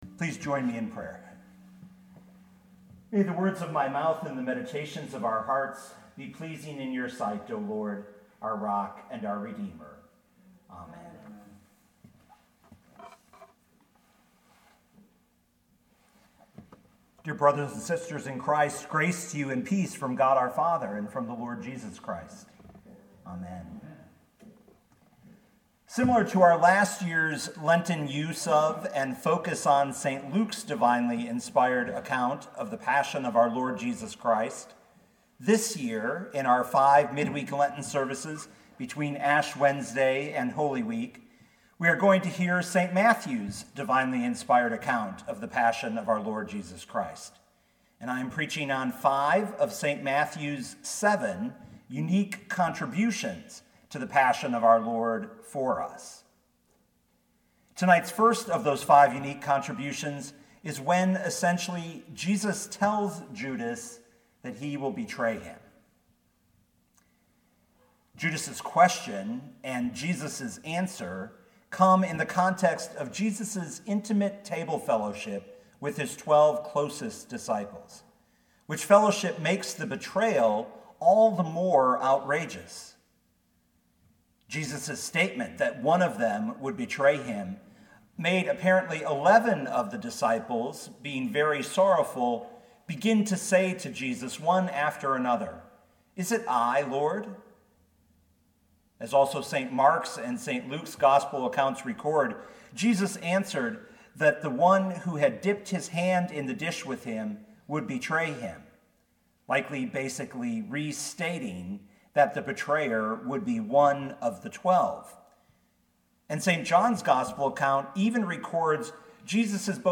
2020 Matthew 26:25 Listen to the sermon with the player below, or, download the audio.